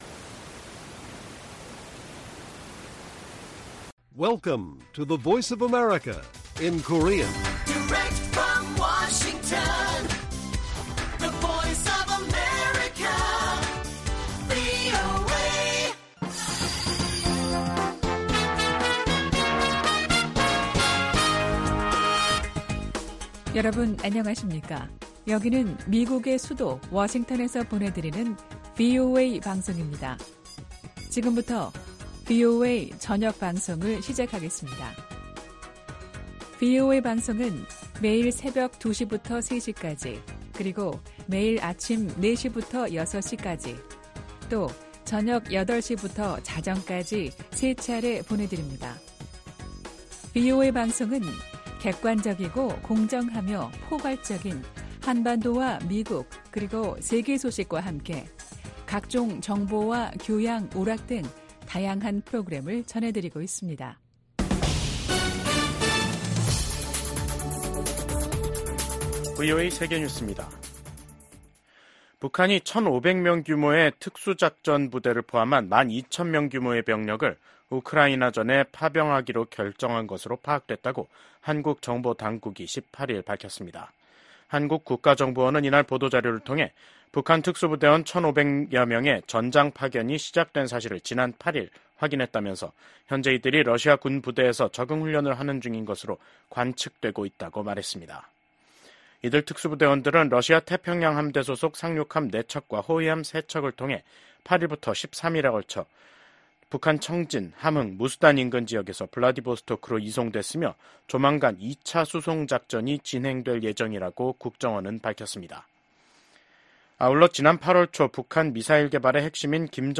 VOA 한국어 간판 뉴스 프로그램 '뉴스 투데이', 2024년 10월 18일 1부 방송입니다. 북한이 한국을 헌법상 적대국으로 규정한 가운데 김정은 국무위원장은 전방부대를 방문해 한국을 위협하는 행보를 보였습니다. 윤석열 한국 대통령은 국가안보실, 국방부, 국가정보원 핵심 관계자 등이 참석한 가운데 ‘북한 전투병의 러시아 파병에 따른 긴급 안보회의’를 열어 대응 방안을 논의했다고 대통령실이 전했습니다.